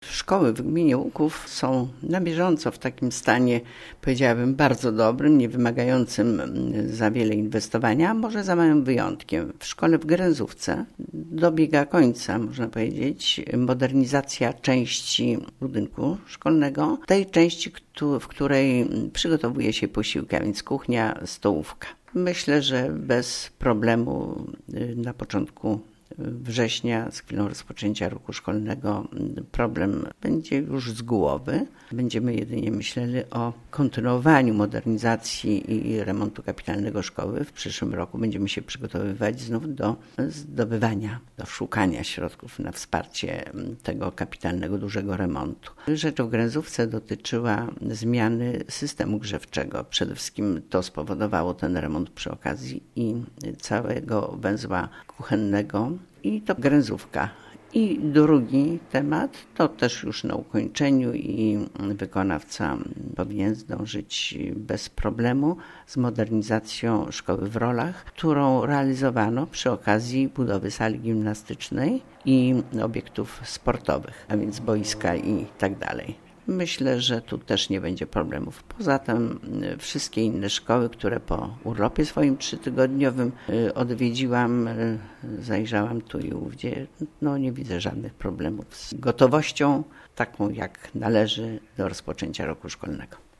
O tym jakie remonty mia�y miejsce w te wakacje w szko�ach Gminy �uk�w informuje: W�jt Gminy �uk�w Kazimiera Go�awska